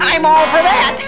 Here you will find tons of great sounds and other downloads from actual episodes of Scooby Doo. Choose from any of over 150 wav files, each of excellent sound quality.